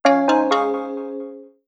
incomingcall.wav